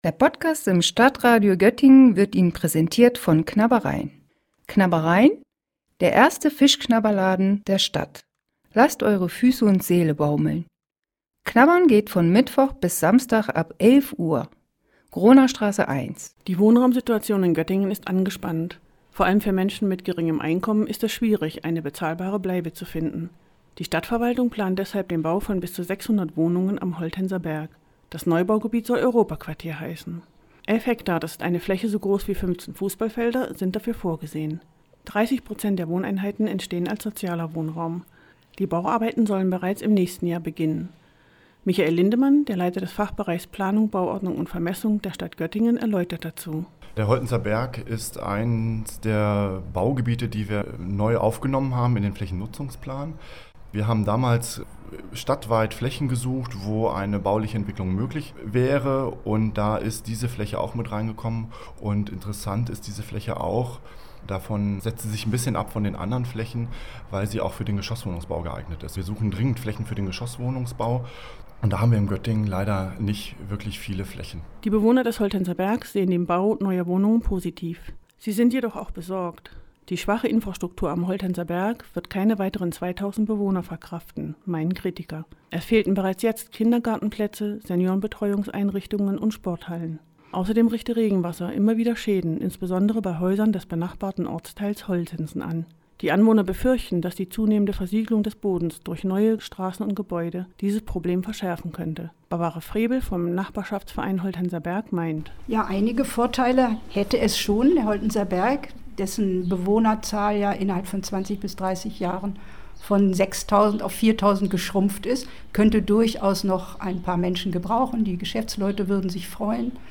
Am 1. Juli fand eine öffentliche Informations- und Diskussionsveranstaltung dazu statt.